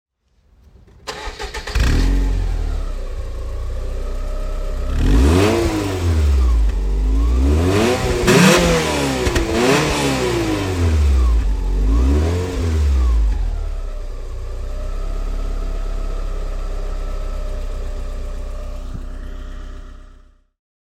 Fiat Coupé 2.0 16V (1995) - Starten und Leerlauf
Fiat_Coupe_1995.mp3